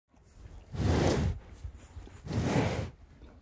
دانلود صدای مزرعه 9 از ساعد نیوز با لینک مستقیم و کیفیت بالا
جلوه های صوتی